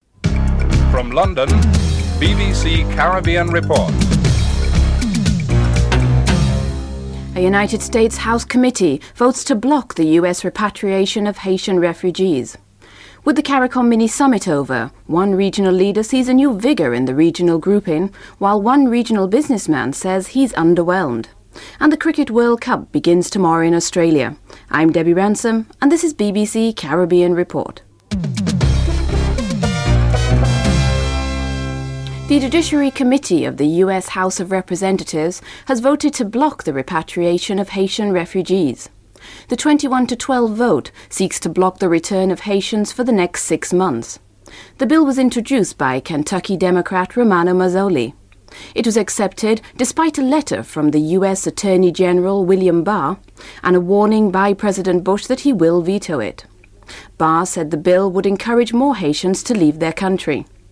1. Headlines (00:00-00:36)
9. Interview with Trinidad and Tobago High Commissioner to London, Ulric Cross on the regional membership which he has high on his list of priorities and on the implcations of the meeting scheduled for June (08:19-09:49)